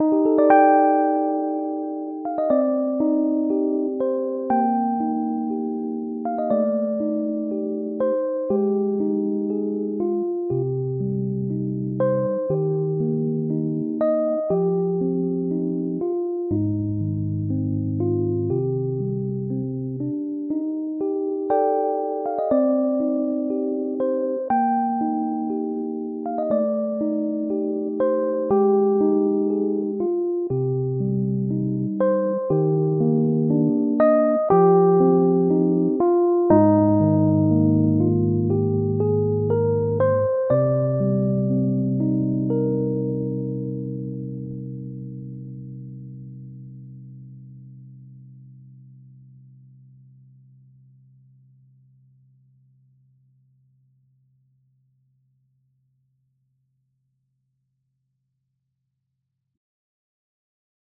标签： piano instrumental
声道立体声